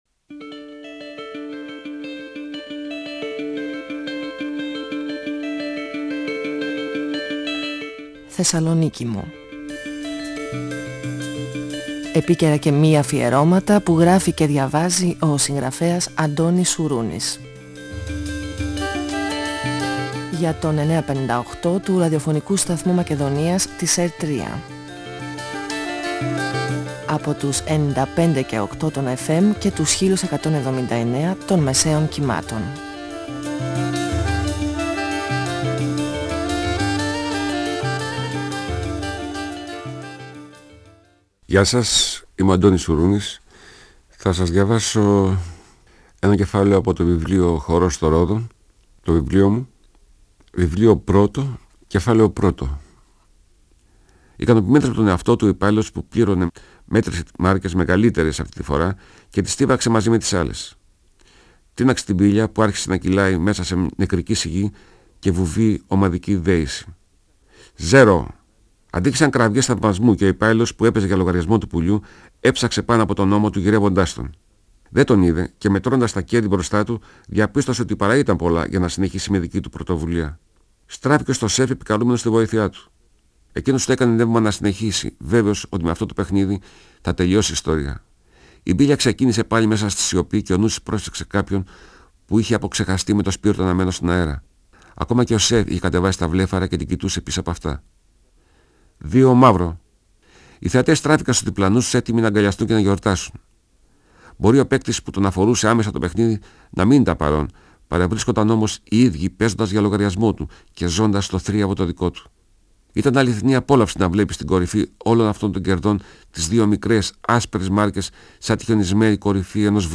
Ο συγγραφέας Αντώνης Σουρούνης (1942-2016) διαβάζει το πρώτο κεφάλαιο από το βιβλίο του «Ο χορός των ρόδων», εκδ. Καστανιώτη, 1994. Η περιπέτεια του Μαξ ή αλλιώς Πουλί, που αφήνει να παίξει στη θέση του ένας υπάλληλος του καζίνου. Το ποσό που κερδίζει είναι τεράστιο αλλά σε λίγο η τύχη για το Πουλί αλλάζει.